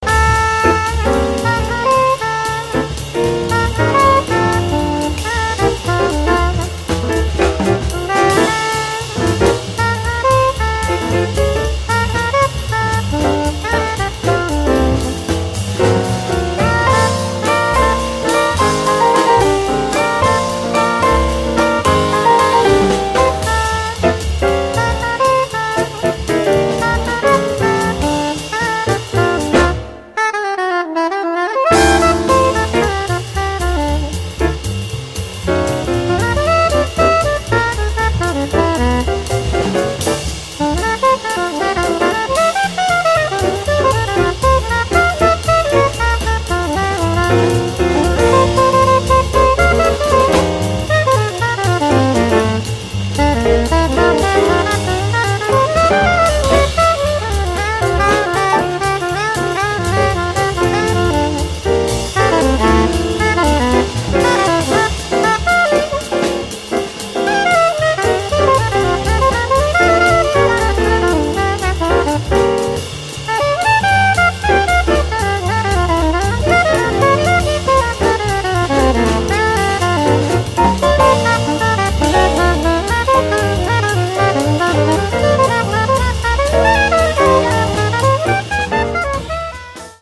sax soprano e tenore
pianoforte
contrabbasso
batteria